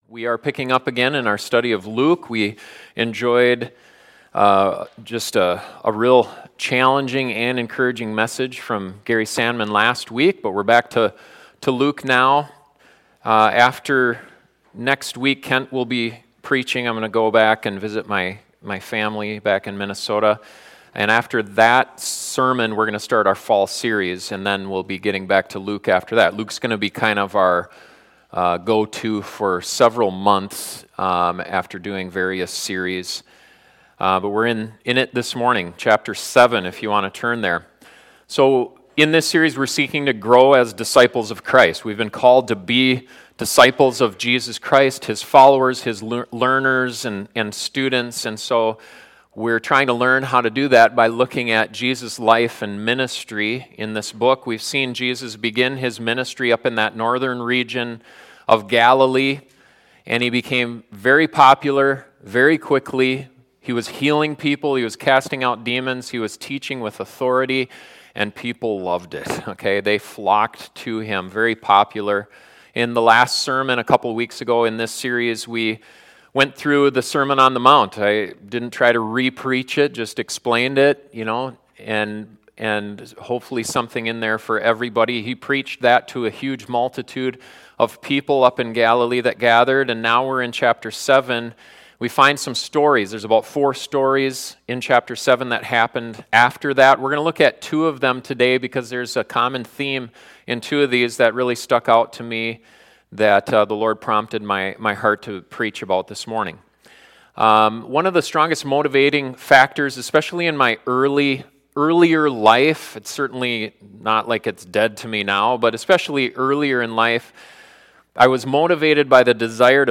This sermon looks at two stories that make it clear.